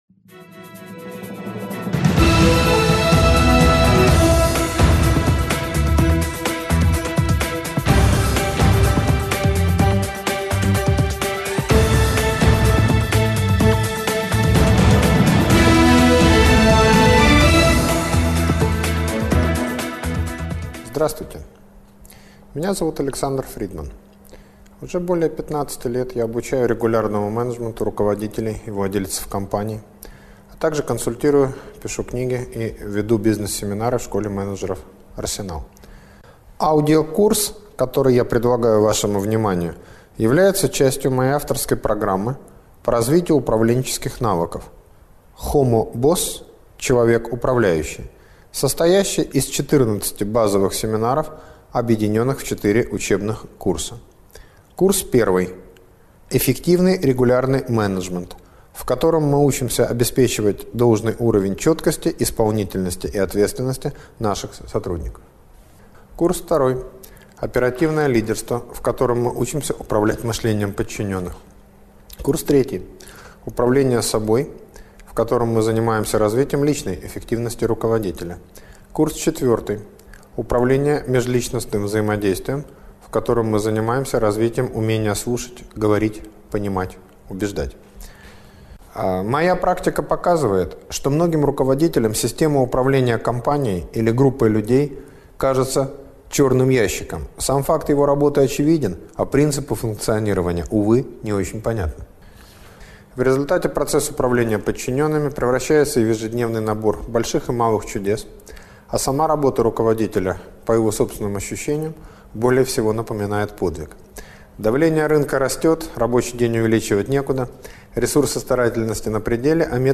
однодневный семинар